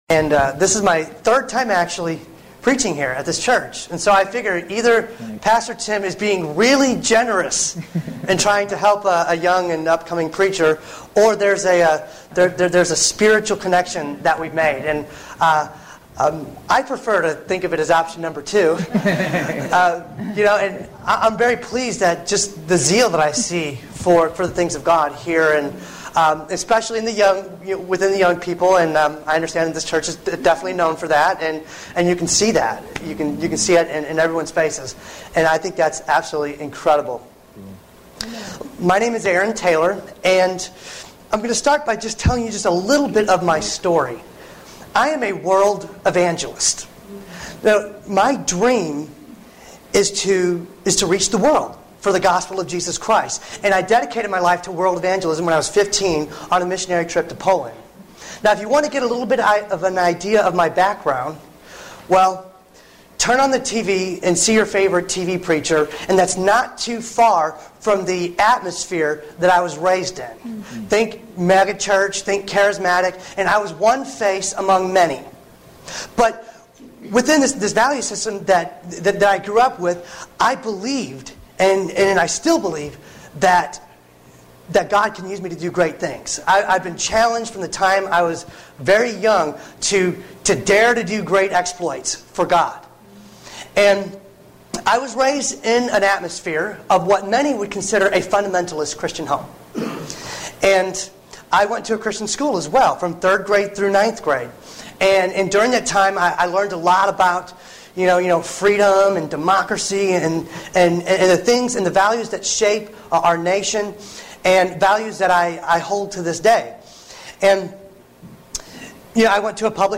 Interview-with-a-Jihadist.mp3